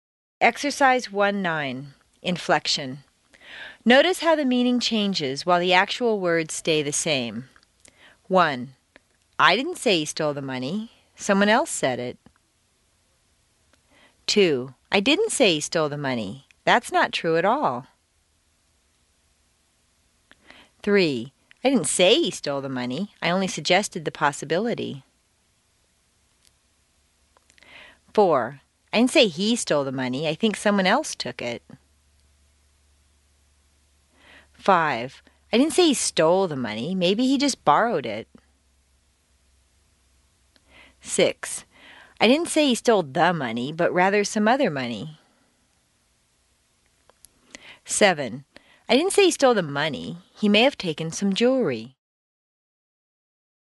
Exercise 1-9: Inflection CD 1 Track 16
Notice how the meaning changes, while the actual words stay the same.